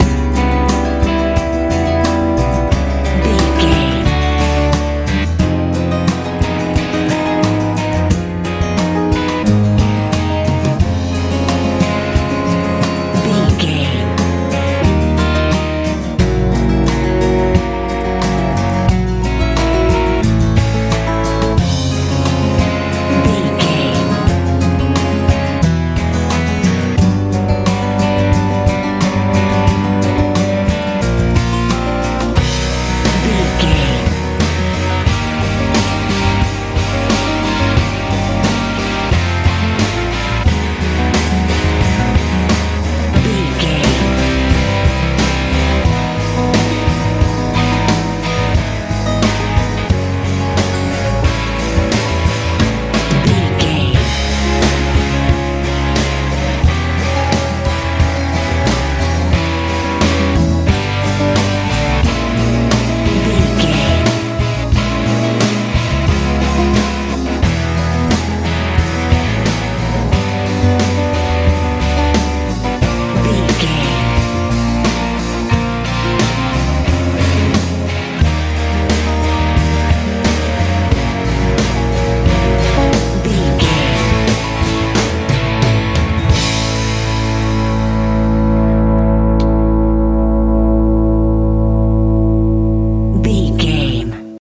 light rock
Ionian/Major
fun
playful
bass guitar
drums
electric guitar
acoustic guitar